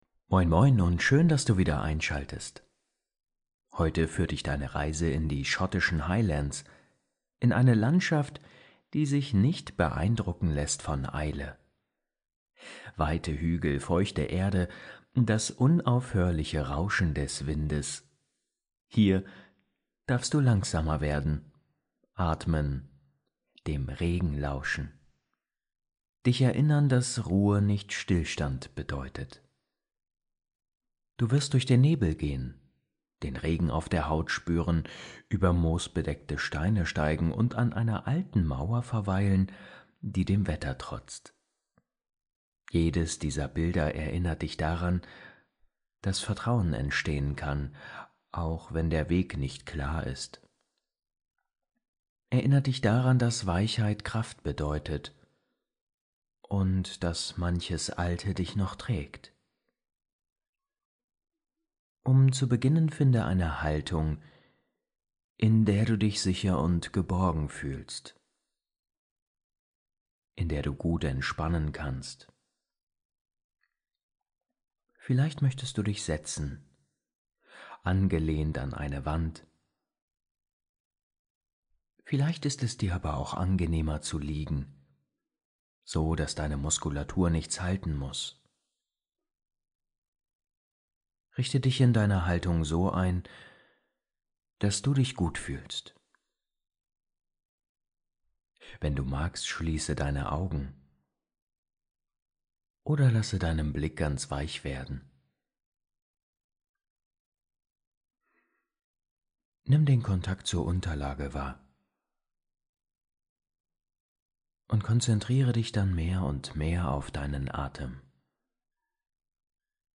Traumreise in die schottischen Highlands ~ Entspannungshelden – Meditationen zum Einschlafen, Traumreisen & Entspannung Podcast